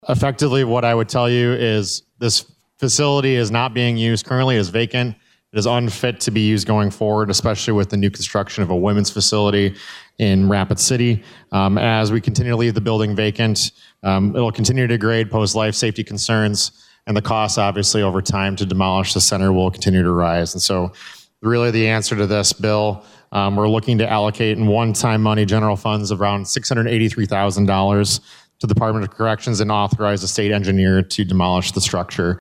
Rep. Erik Muckey from Sioux Falls spoke in favor of House Bill 1046.